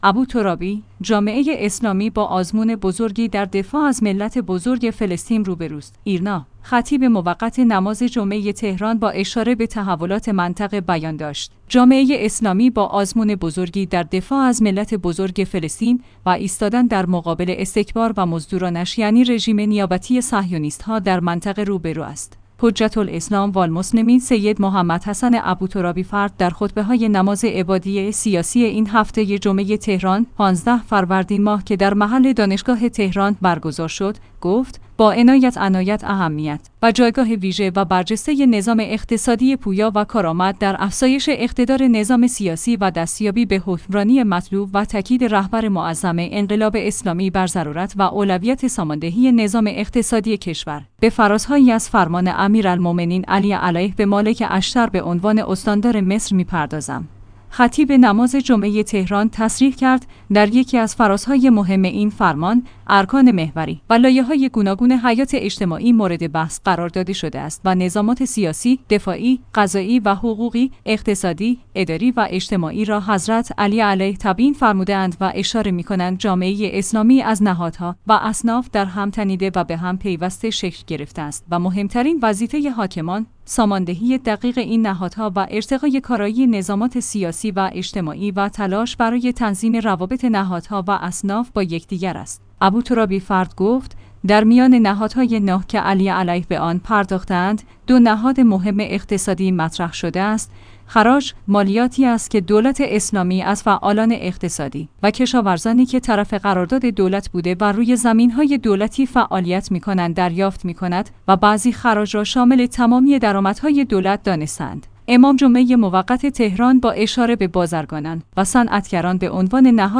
ایرنا/ خطیب موقت نماز جمعه تهران با اشاره به تحولات منطقه بیان داشت: جامعه اسلامی با آزمون بزرگی در دفاع از ملت بزرگ فلسطین و ایستادن در مقابل استکبار و مزدورانش یعنی رژیم نیابتی صهیونیست‌ها در منطقه روبه‌رو است.